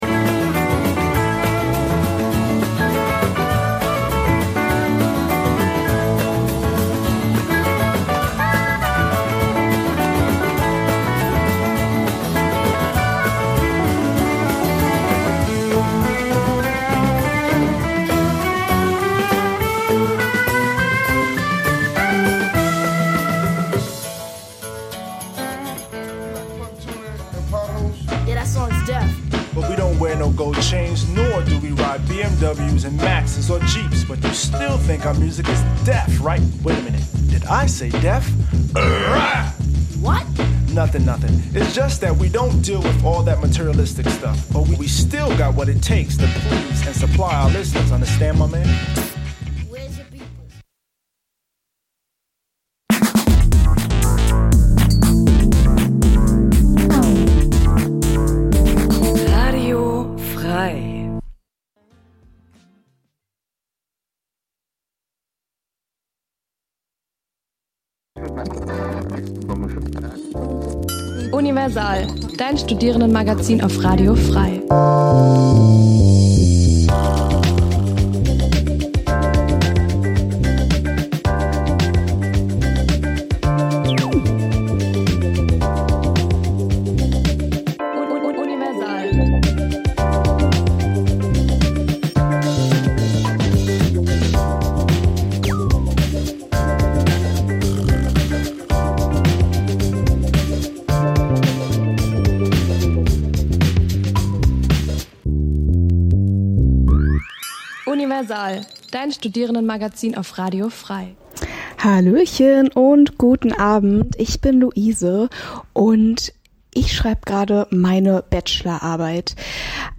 Die Sendungen werden gemeinsam vorbereitet - die Beitr�ge werden live im Studio pr�sentiert.
Studentisches Magazin Dein Browser kann kein HTML5-Audio.